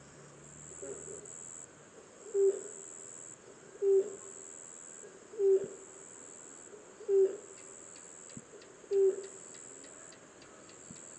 ズグロミゾゴイ
【分類】 ペリカン目 サギ科 ミゾゴイ属 ズグロミゾゴイ 【分布】四国(迷鳥：徳島)、利尻島(迷鳥)、宮古島(留鳥)、八重山諸島(留鳥) 【生息環境】森林に生息 徳島県阿南市ではヨシ原に出現 【全長】49cm 【主な食べ物】カエル、トカゲ 【保全状況】絶滅危惧Ⅱ類（VU） ・・・危急 【鳴き声】地鳴き 【聞きなし】「ゴッゴッゴッ」「ボッボッ」